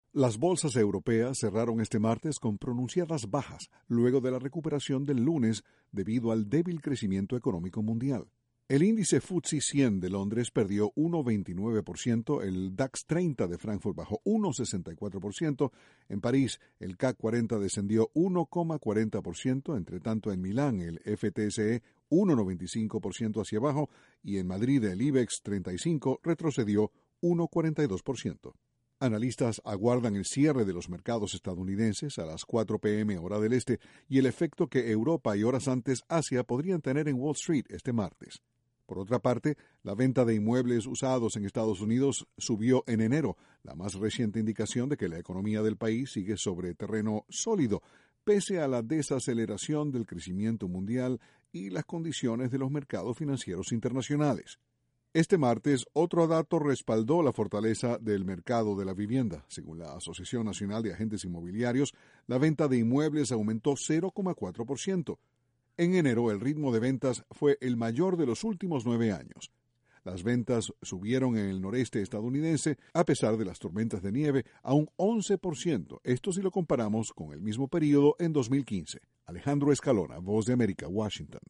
Bajan las bolsas europeas y en Estados Unidos aumenta la venta de bienes inmuebles. Desde la Voz de América, Washington, informa